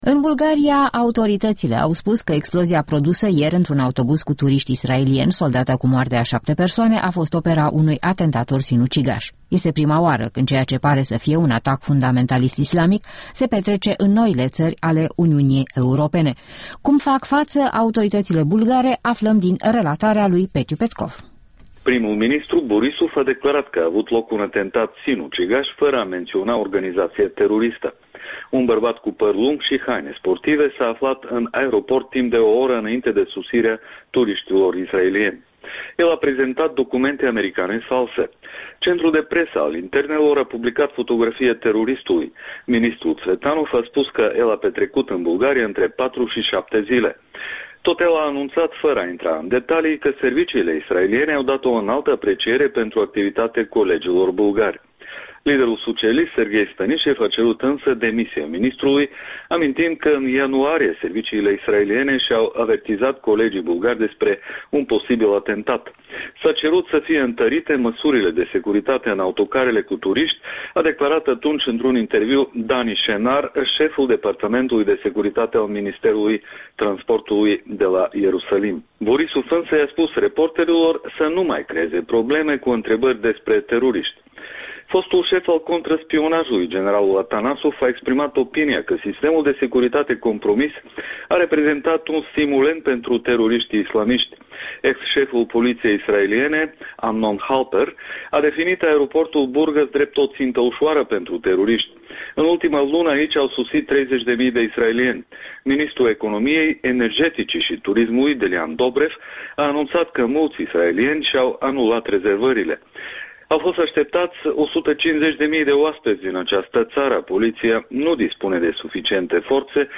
După atentatul terorist de la Burgas - o corespondență de la Sofia